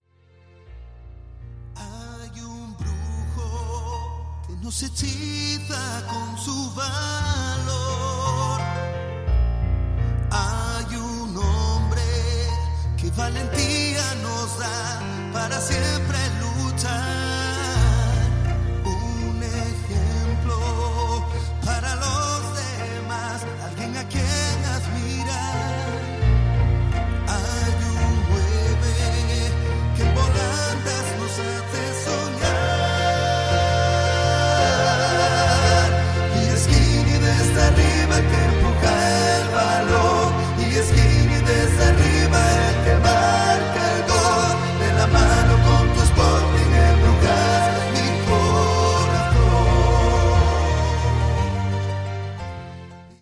el sonido de un piano